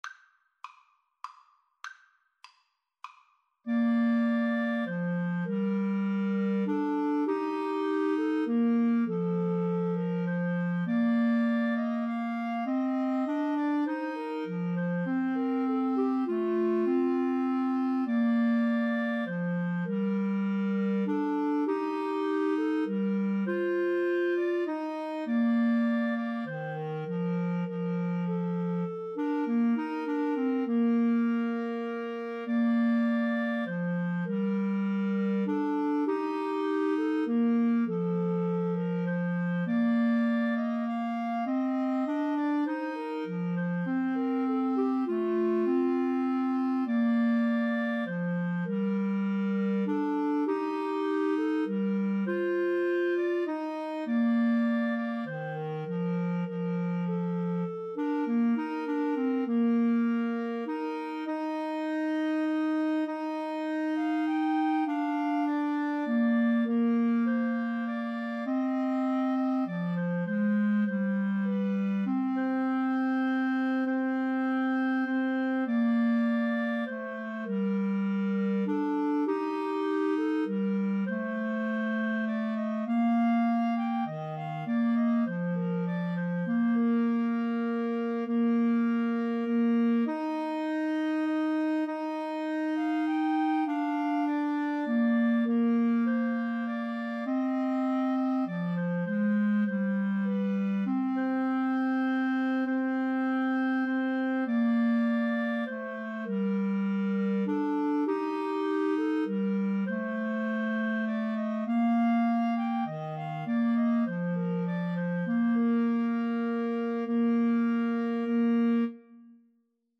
Bb major (Sounding Pitch) C major (Clarinet in Bb) (View more Bb major Music for Clarinet Trio )
3/4 (View more 3/4 Music)
Clarinet Trio  (View more Intermediate Clarinet Trio Music)
Classical (View more Classical Clarinet Trio Music)